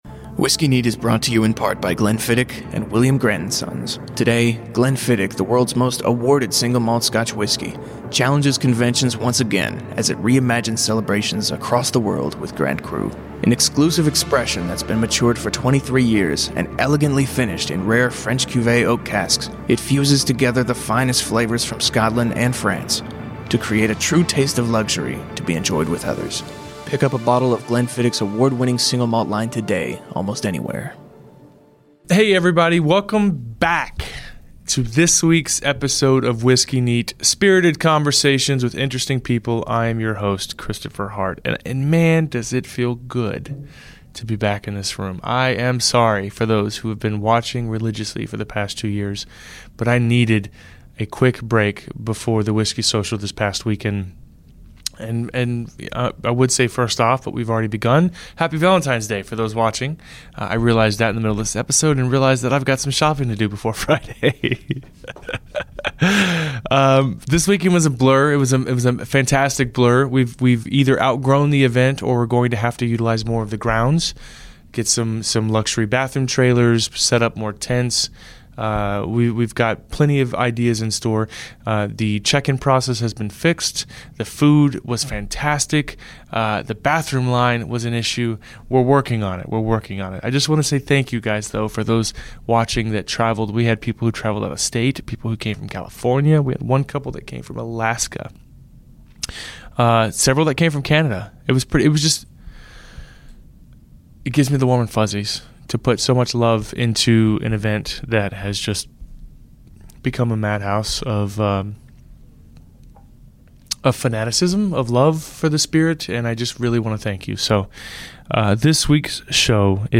Whiskey Neat is a Radio show on iTunes and ESPN 97.5 FM in Houston and is brought to you every week by the following sponsors.